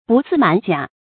不自滿假 注音： ㄅㄨˋ ㄗㄧˋ ㄇㄢˇ ㄐㄧㄚˇ 讀音讀法： 意思解釋： 不自滿，不自大。